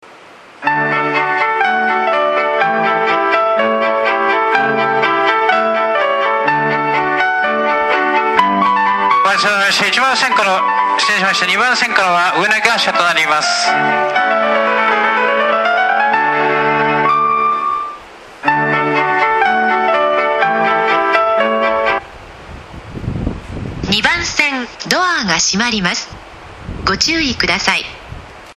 発車メロディー  フルコーラスです。